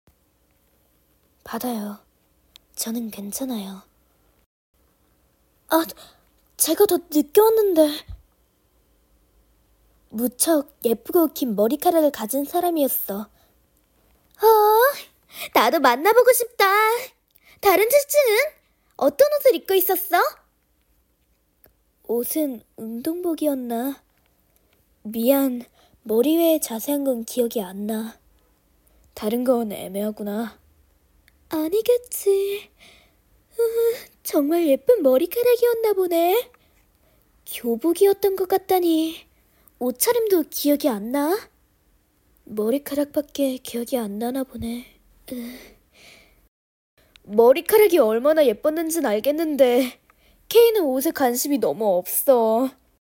4컷만화 제71화 '머리카락이 예쁜 그 사람' 1인다역 더빙